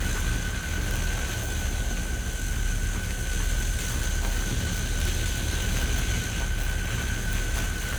Weapon 12 Loop (Laser).wav